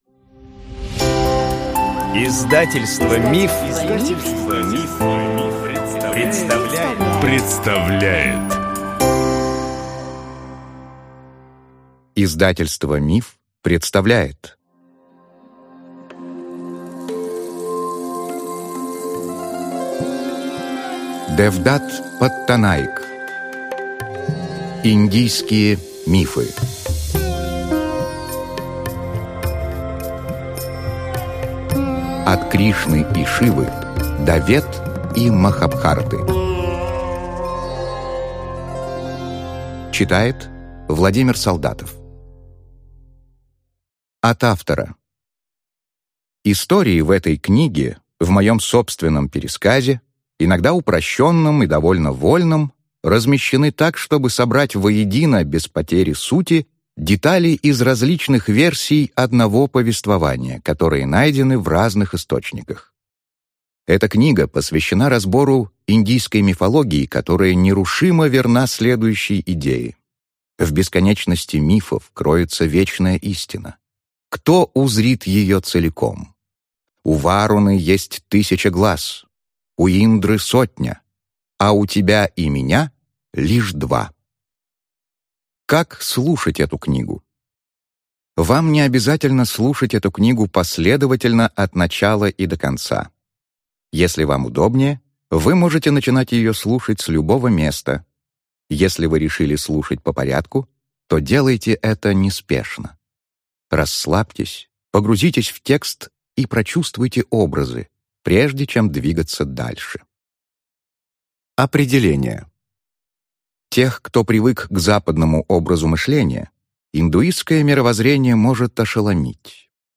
Аудиокнига Индийские мифы. От Кришны и Шивы до Вед и Махабхараты | Библиотека аудиокниг